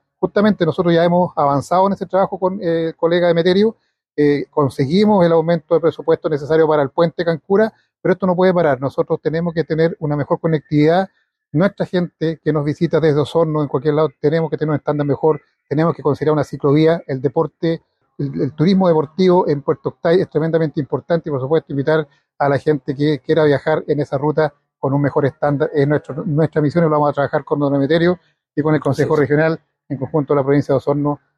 En la última sesión plenaria del Consejo Regional de Los Lagos, realizada en la comuna de Puerto Octay, se discutió la posibilidad de mejorar la ruta U-55, que conecta las comunas de Puerto Octay y Osorno.
Finalmente, el alcalde de Puerto Octay, Gerardo Gunckel, destacó el trabajo conjunto que ya se ha iniciado para mejorar la infraestructura vial de la zona, además enfatizó en la necesidad de considerar una ciclovía, ante el aumento del turismo deportivo en Puerto Octay.